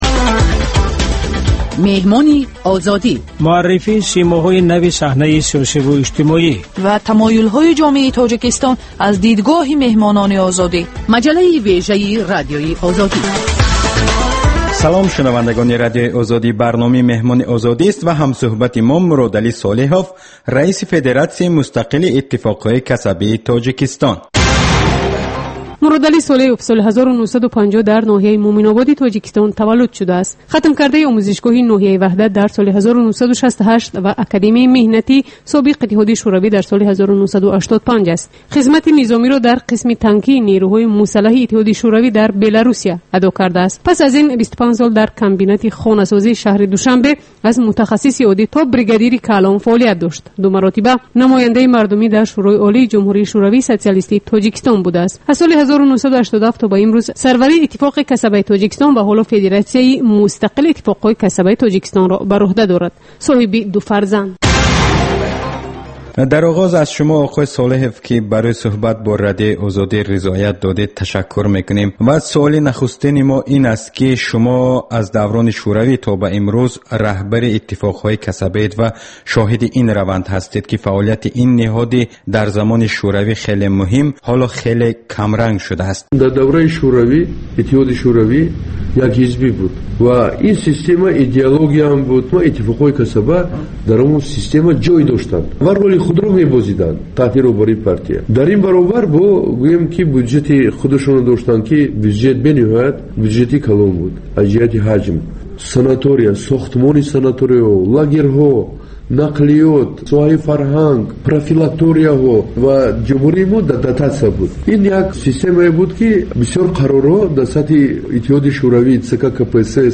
Сӯҳбати ошкоро бо чеҳраҳои саршинос ва мӯътабари Тоҷикистон дар мавзӯъҳои гуногун, аз ҷумла зиндагии хусусӣ.